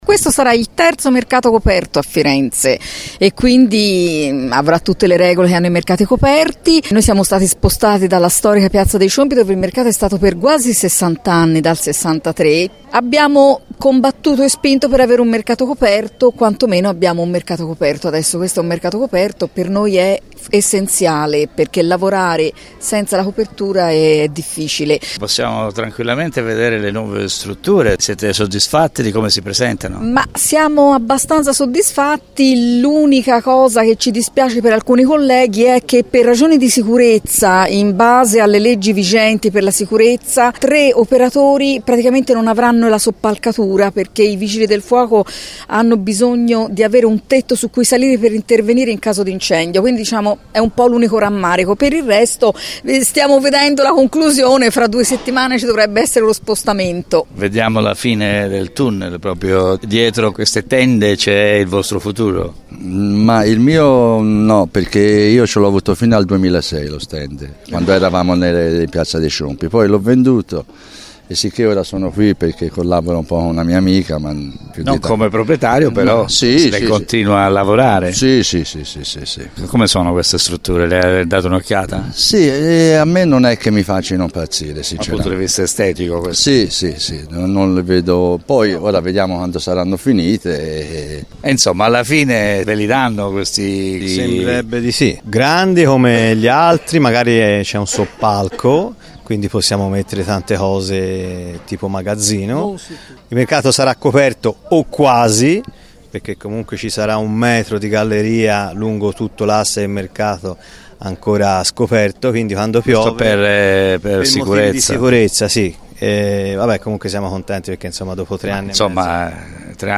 alcuni degli antiquari del Mercato delle Pulci